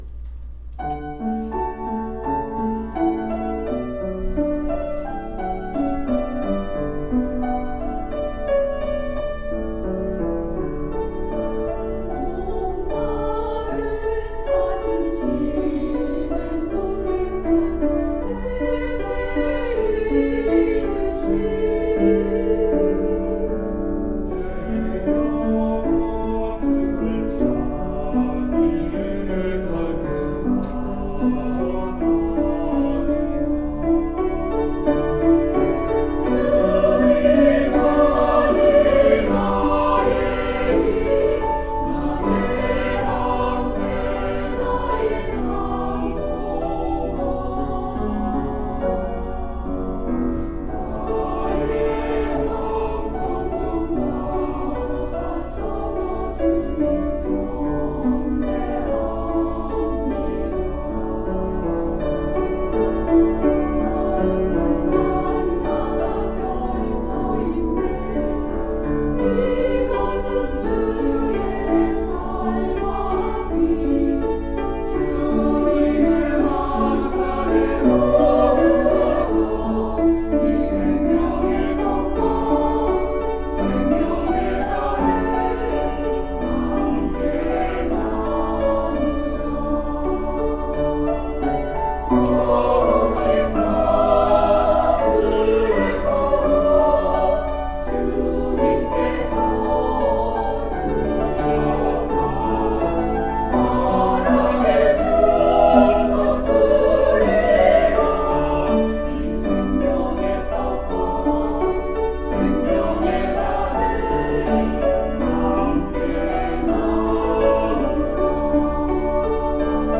찬양대
[주일 찬양] 목마른 사슴이